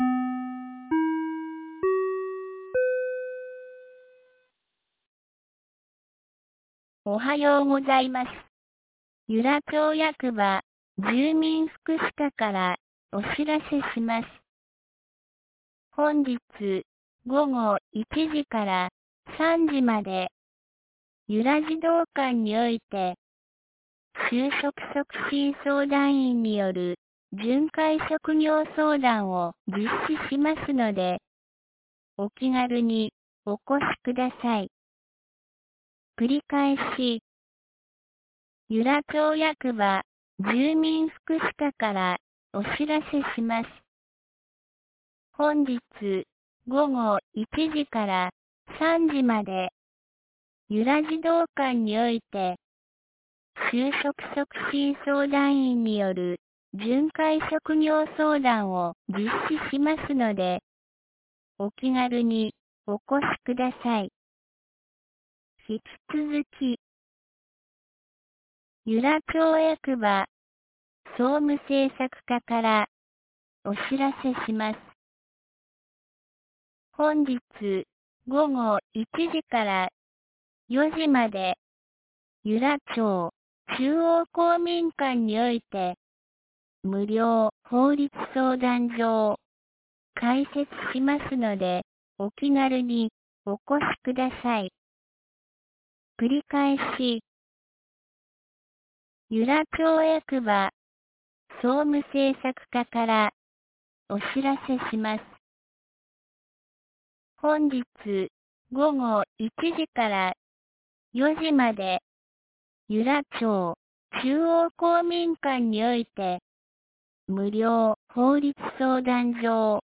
2020年06月11日 07時52分に、由良町から全地区へ放送がありました。